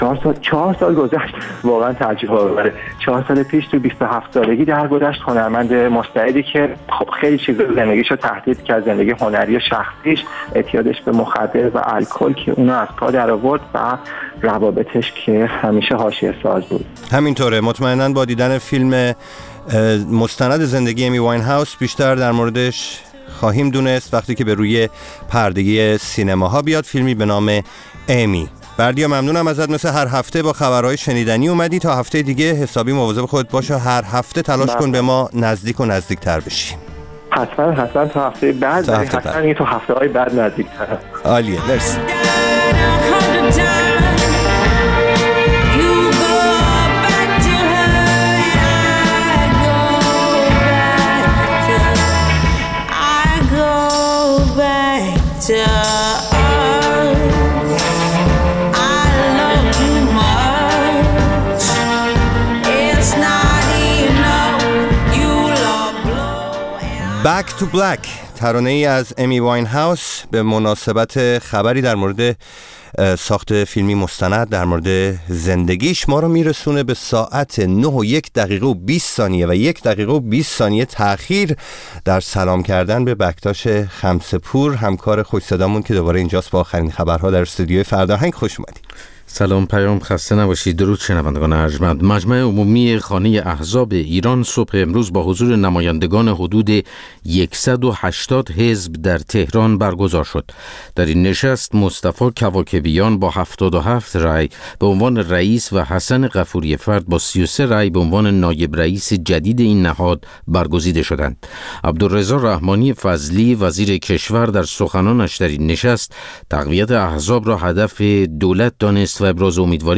برنامه زنده بخش موسیقی رادیو فردا.